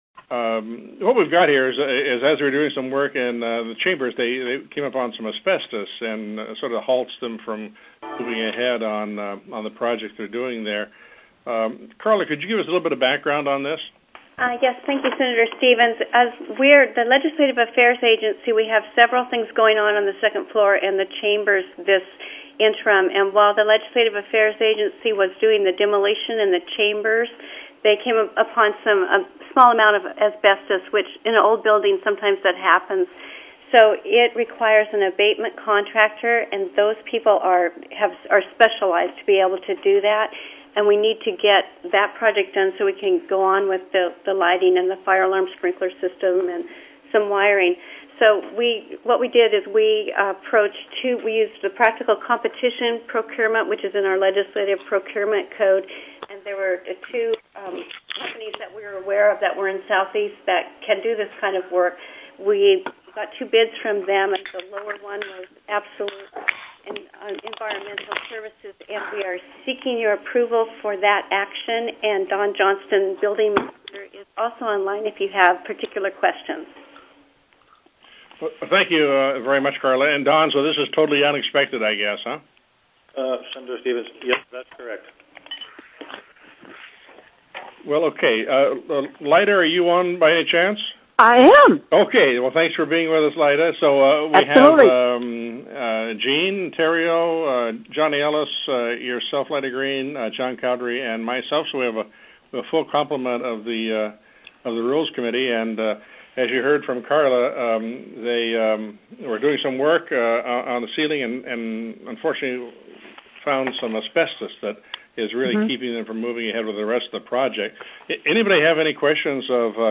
SENATE RULES STANDING COMMITTEE